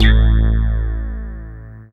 bseTTE48016moog-A.wav